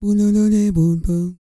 E-CROON 3013.wav